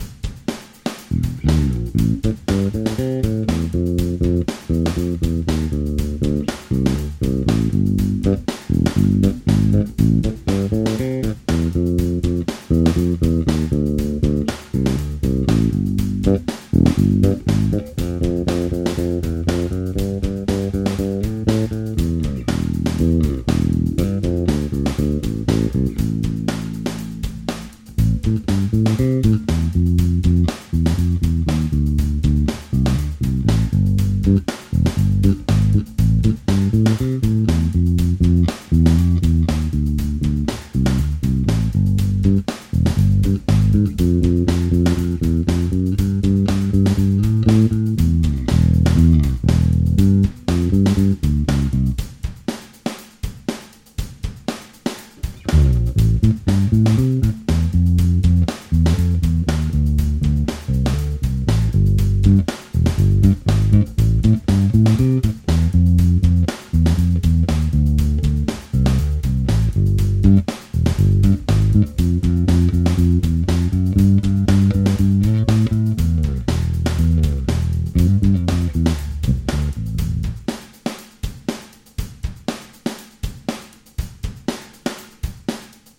Fender Jazz Bass USA Standard
Oba nástroje mají na sobě skoro rok staré struny Daddario nickel wound .45. Pokud by měl někdo pochybnosti - nastavení mixu je stejné.